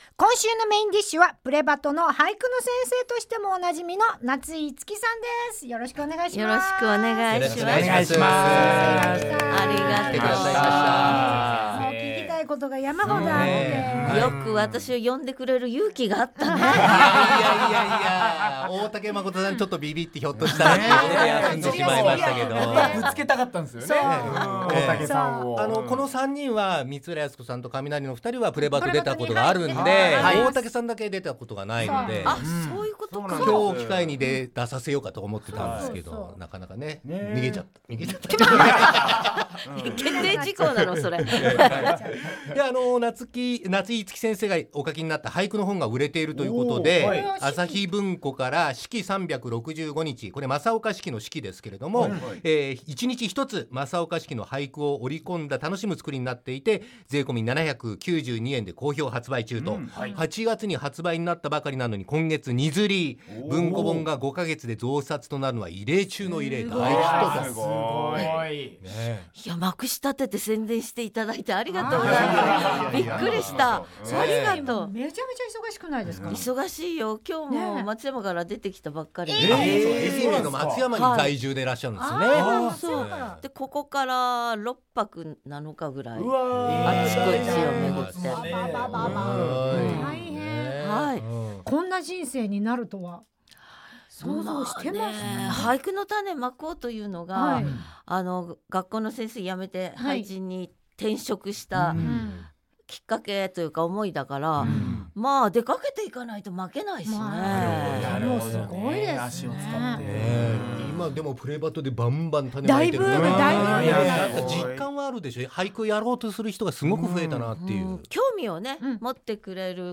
番組のメインを飾るゲストが登場！